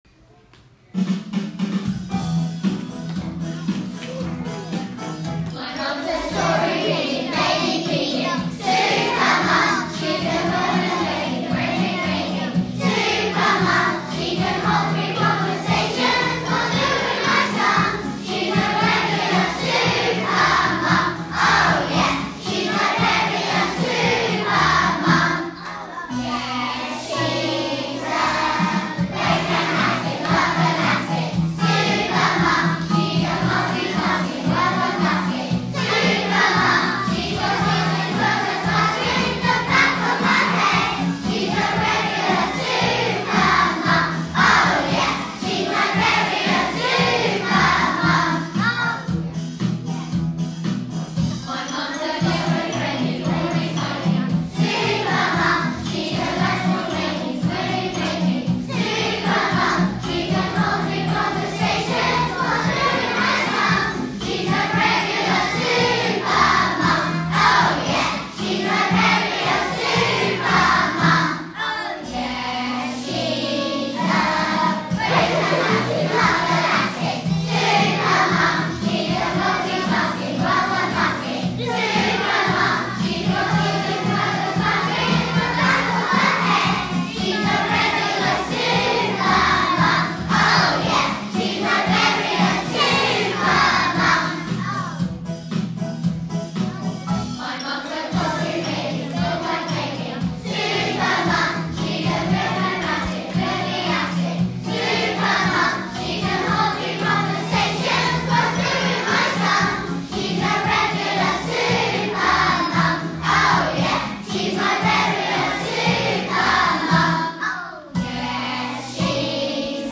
Please click image to hear the children of The Mead singing their 'Super Mum' song.